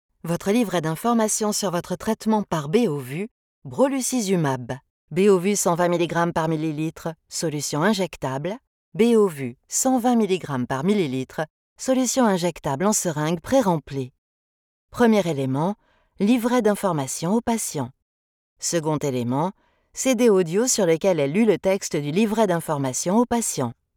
Female
Approachable, Confident, Corporate, Friendly, Reassuring, Versatile, Warm
Documentary-End Of Universe.mp3
Microphone: Neumann TLM 103
Audio equipment: RME Fireface UC, separate Soundproof whisper room